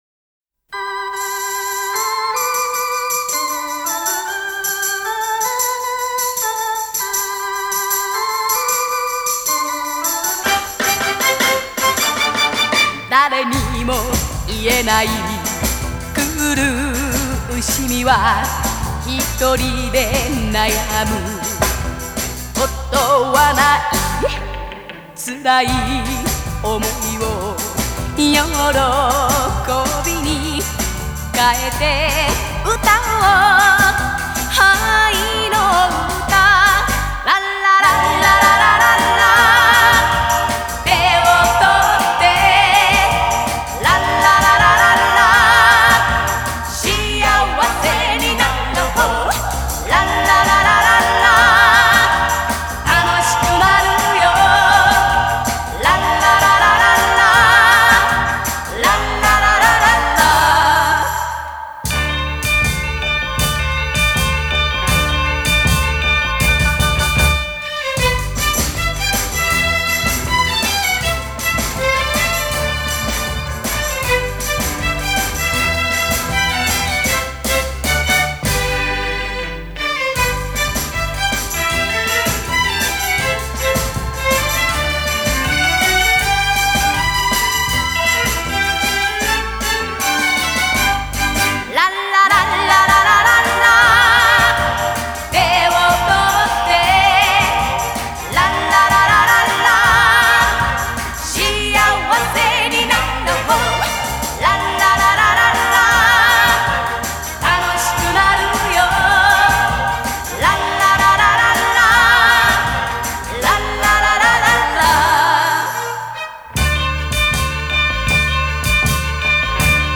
Обе песни исполняют певицы.